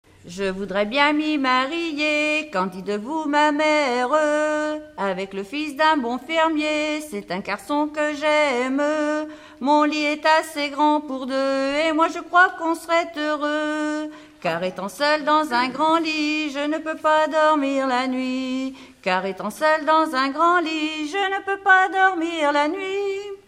Note en duo
Genre dialogue
Pièce musicale inédite